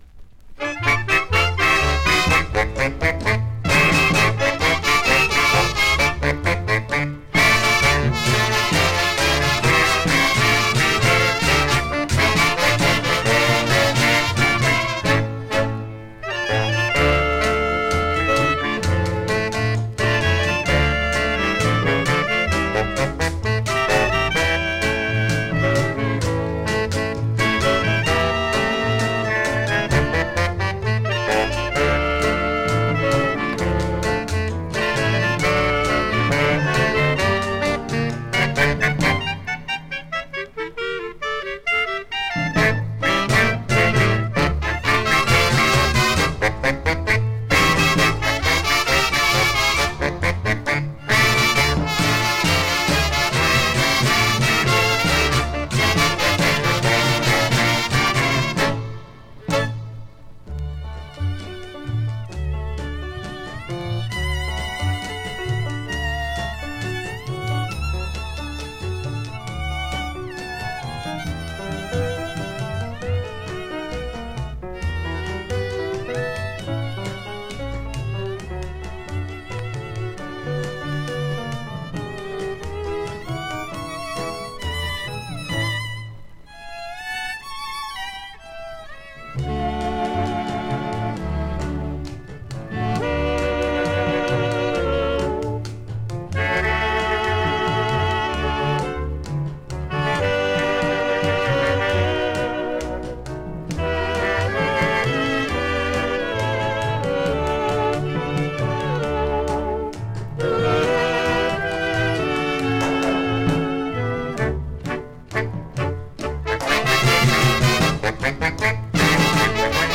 Just remember, the melody tends to heat up at the end.